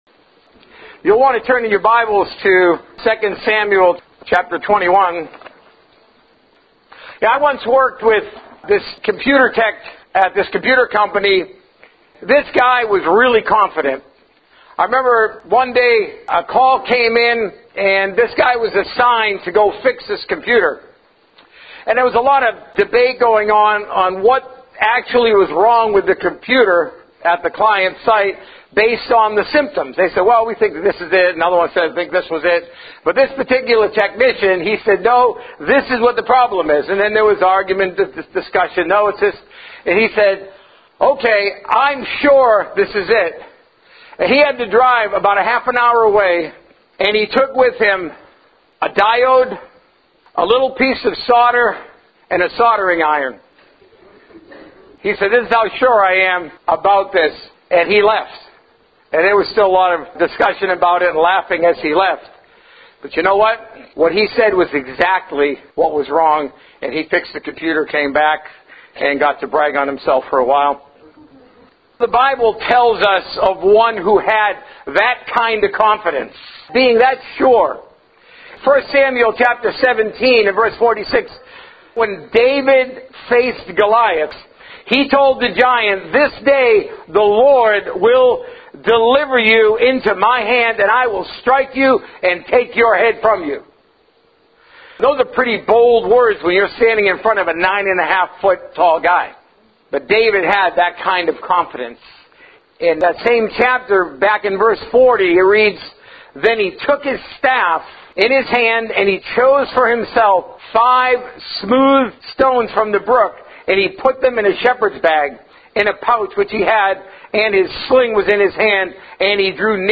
A message from the series "Ministry Power."